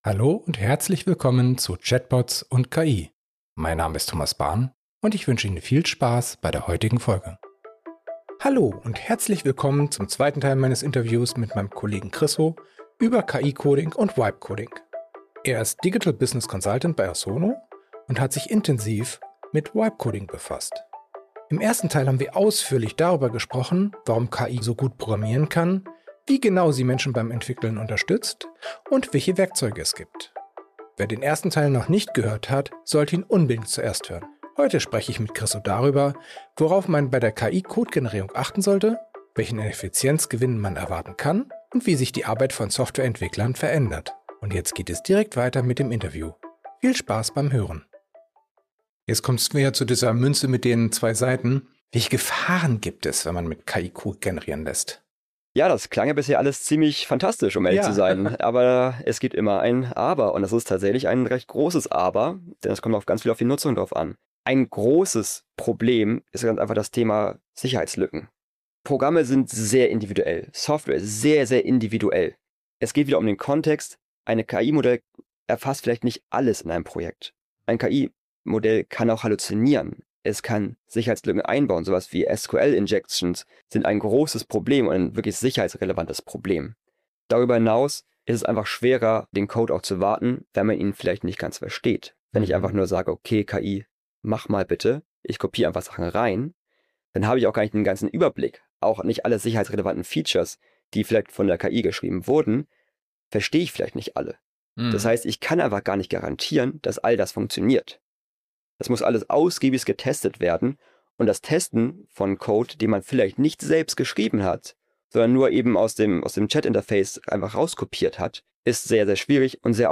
In diesem dreiteiligen Interview beleuchten wir die Unterschiede zwischen Agenten für den privaten und geschäftlichen Einsatz, die Chancen, die sie Unternehmen eröffnen, sowie die technischen, organisatorischen und kulturellen Herausforderungen, die es zu meistern gilt, damit KI-Agenten nicht im Pilotstadium steckenbleiben, sondern echten Mehrwert schaffen. Im dritten und letzten Teil gehen wir die Einführung eines KI-Agenten Schritt für Schritt durch und diskutieren die technischen, organisatorischen sowie kulturellen Herausforderungen, die Unternehmen dabei bewältigen müssen.